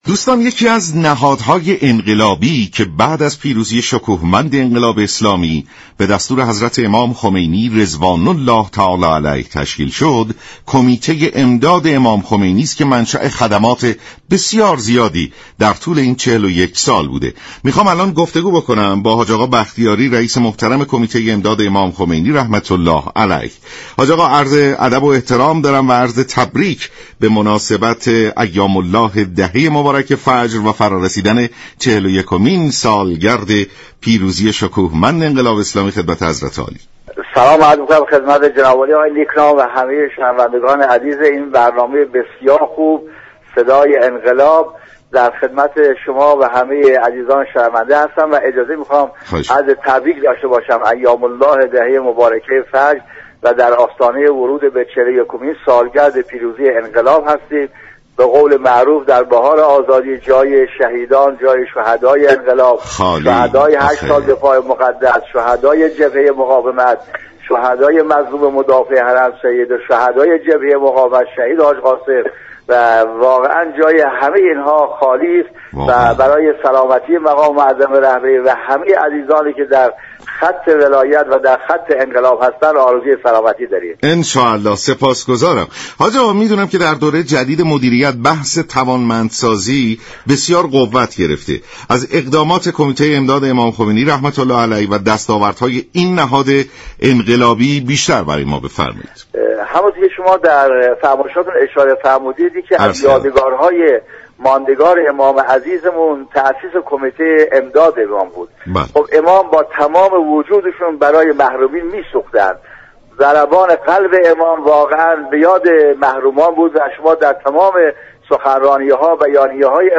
به گزارش شبكه رادیویی ایران، «مرتضی بختیاری» رییس كمیته امداد امام خمینی (ره) در برنامه «صبح انقلاب» درباره دستاوردهای این نهاد انقلابی گفت: كمیته امداد امام خمینی (ره) كه با هدف حمایت از محرومان و اقشار ضعیف تاسیس شده، اكنون بحث توانمندسازی را در اولویت كار خود قرار داده است.
برنامه «صبح انقلاب» در دهه مبارك فجر، شنبه تا پنج شنبه ساعت 6:35 از رادیو ایران پخش می شود.